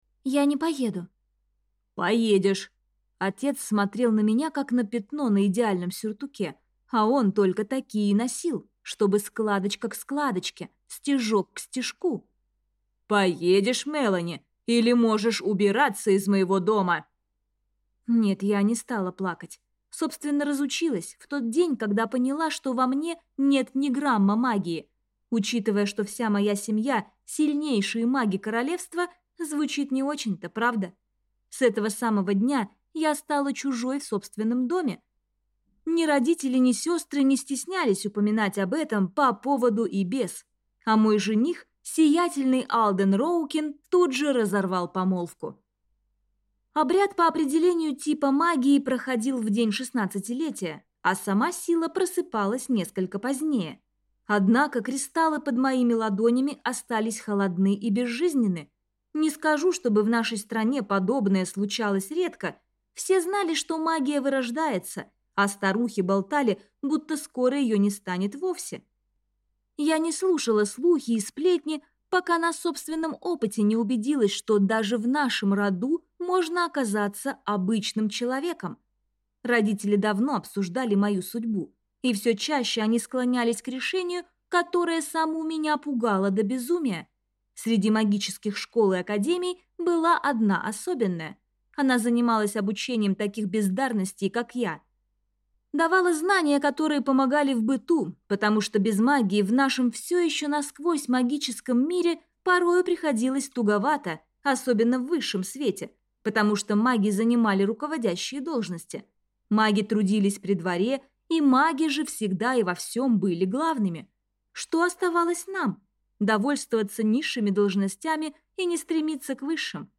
Аудиокнига Академия бездарностей | Библиотека аудиокниг